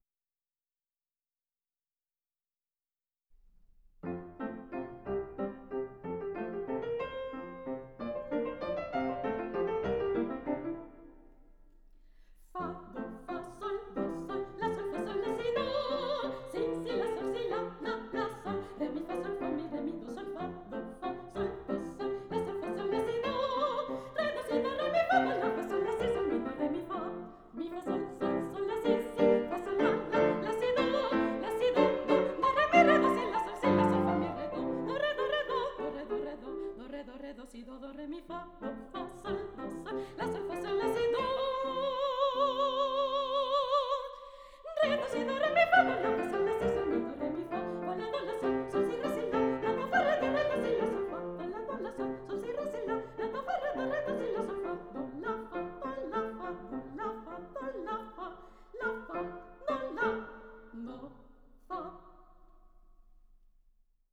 aufgenommen in den Tonstudios des Spanischen National Radios
Luigi Arditi, Fünf Lieder für Koloratursopran und Klavier: